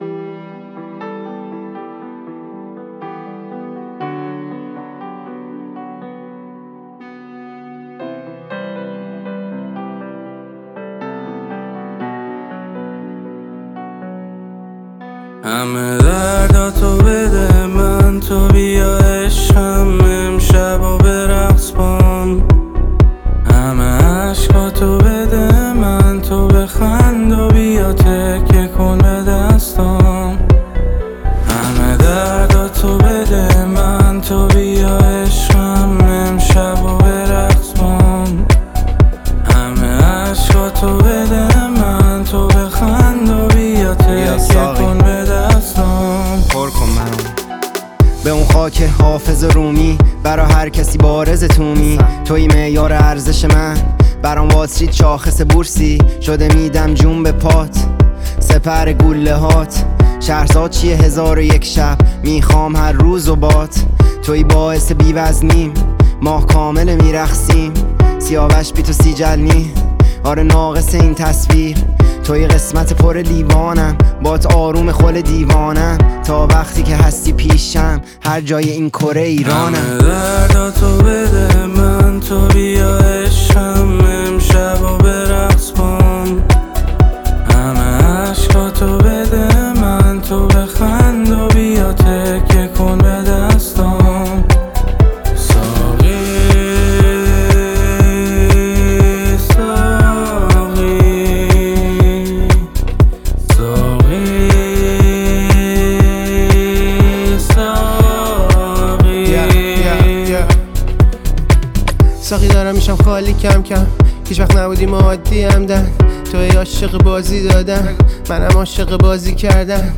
آهنگ جدید عاشقانه و احساسی